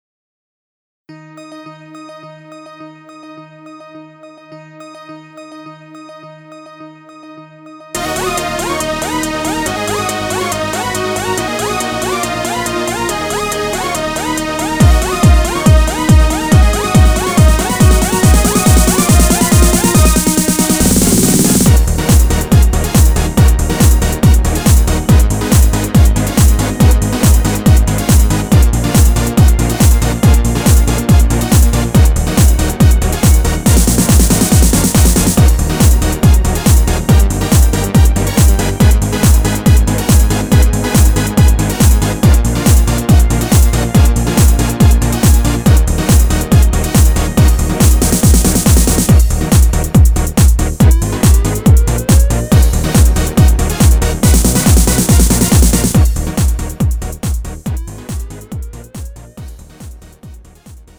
음정 (남자)
장르 가요 구분 Lite MR